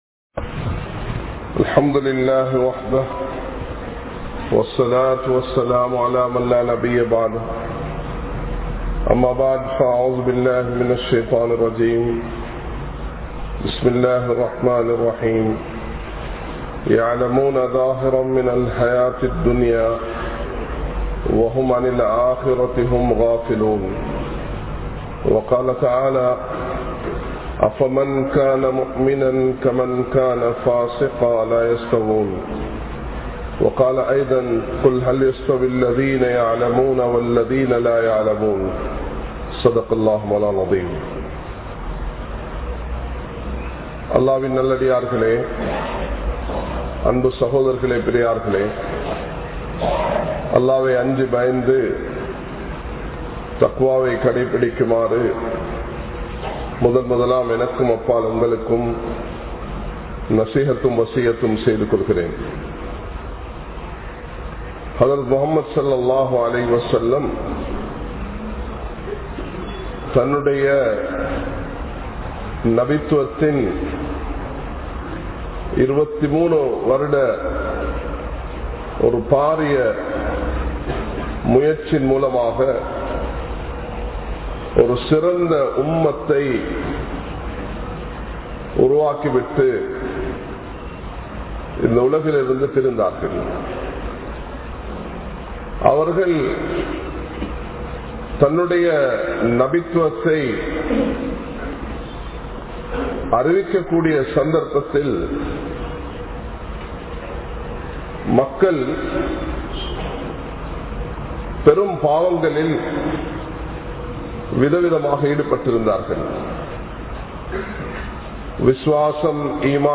Muhammath(SAW)Avarhalin Akhlaaq (முஹம்மத்(ஸல்)அவர்களின் அஹ்லாக்) | Audio Bayans | All Ceylon Muslim Youth Community | Addalaichenai